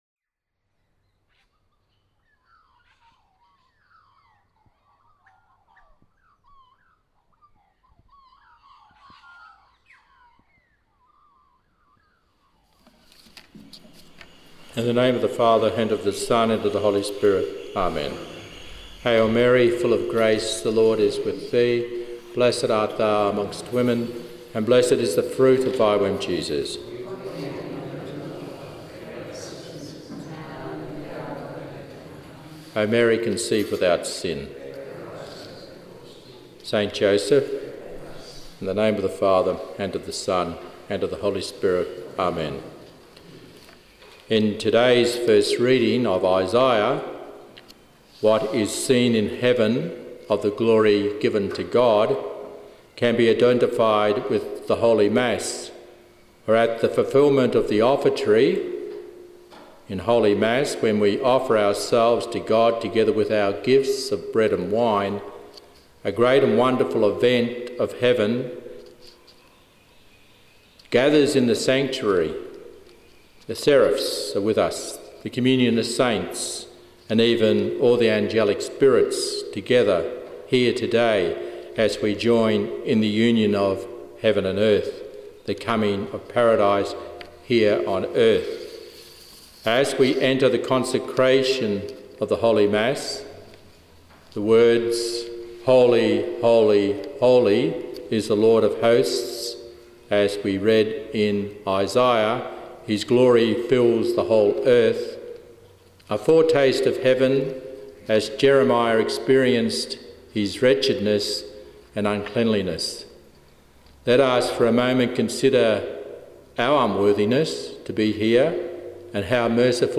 Mass: 5th Sunday in Ordinary Time – Sunday – Form: OF